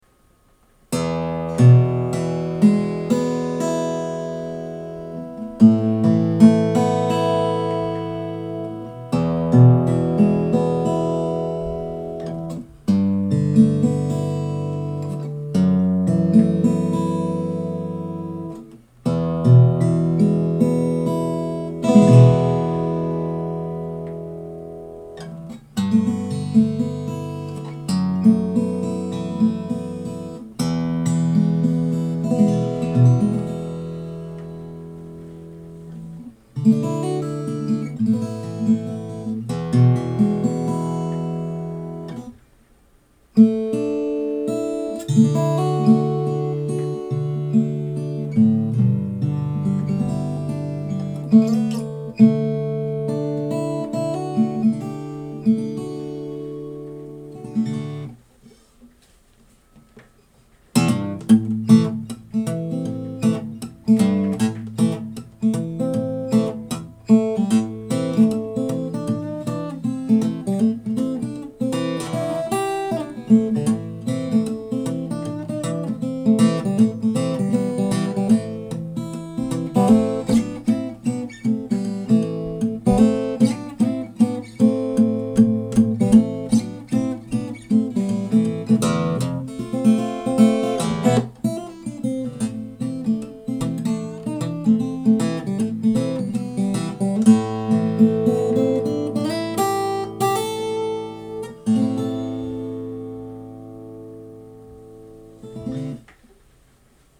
ニューヨーカーモデルのようなパーラーに近いような１２フレットジョイントのボディです。 普通のOMの低音とは違う深い低音が出るモデルです。